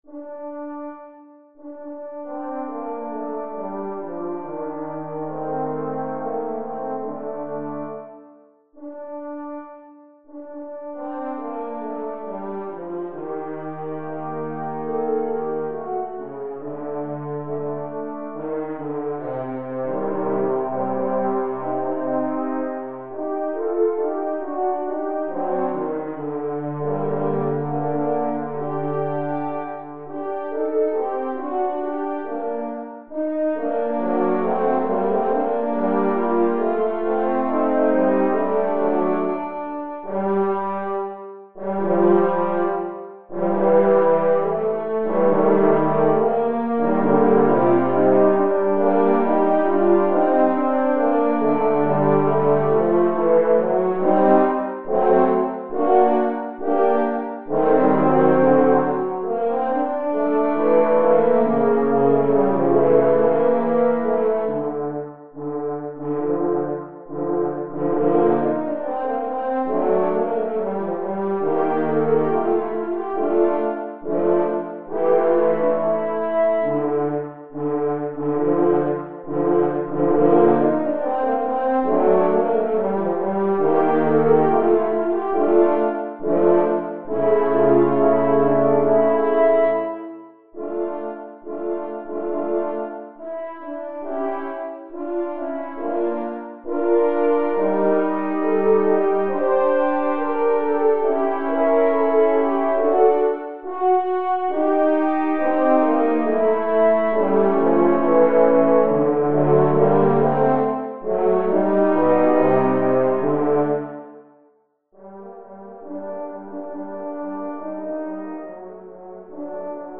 Viking Horn Trio No. 4
Viking Horn Trio No. 4 (The One-Eyed Stranger) (MIDI)